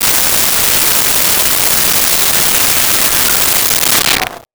Alien Wierdness Descending 02
Alien Wierdness Descending 02.wav